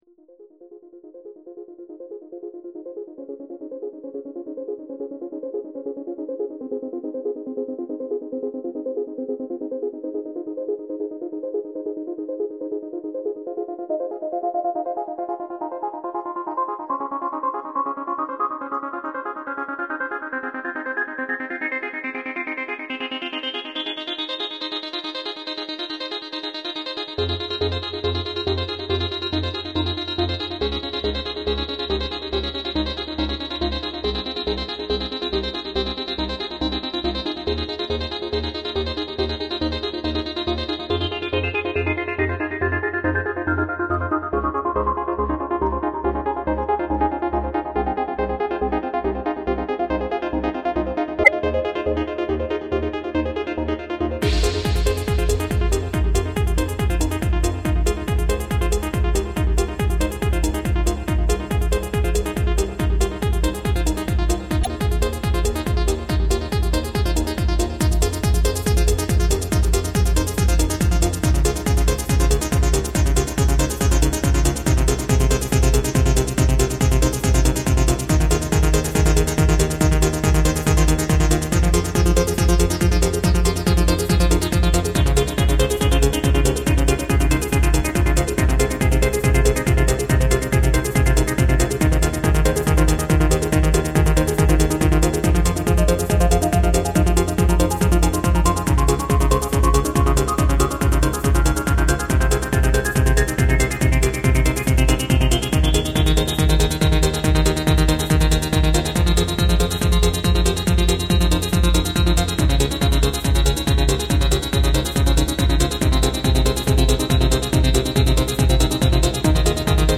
• Jakość: 44kHz, Stereo